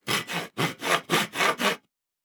Saw Wood 5_1.wav